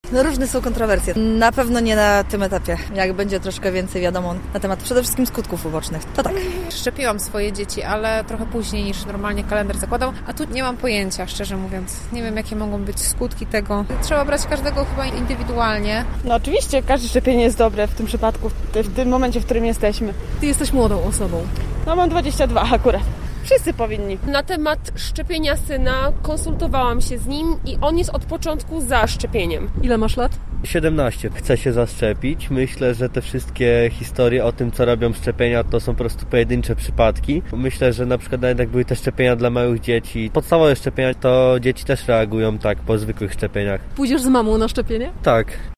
O szczepienia nastolatków zapytaliśmy zielonogórzan.